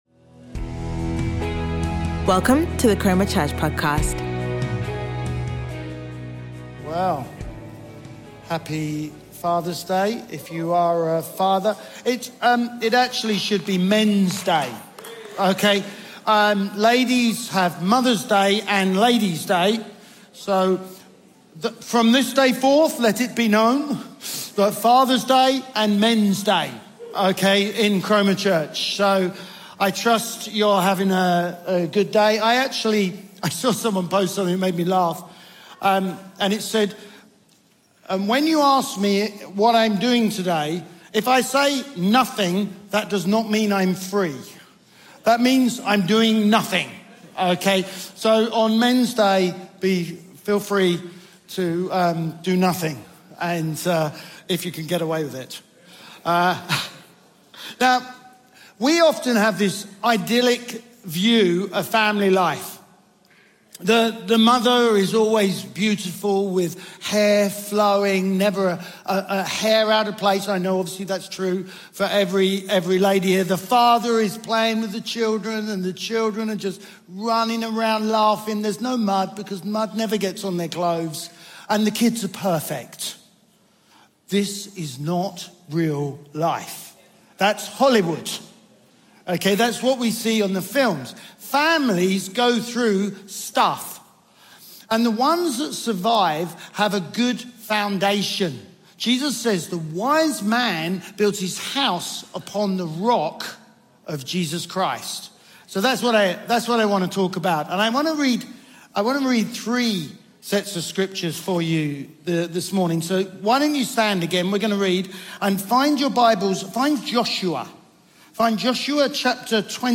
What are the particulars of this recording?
Chroma Church Live Stream